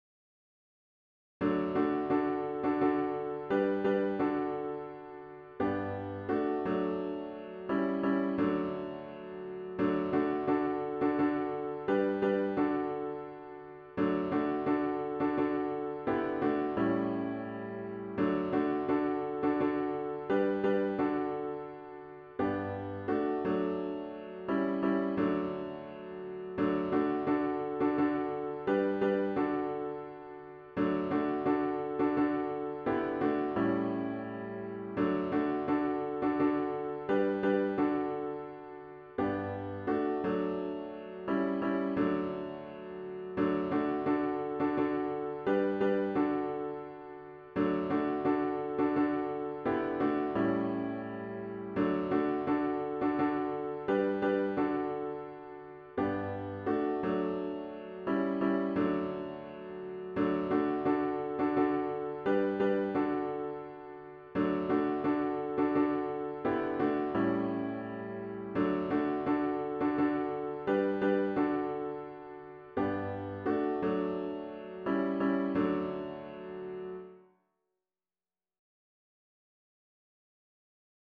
*HYMN “Kum ba Yah” GtG 472    Words and Music: African American spiritual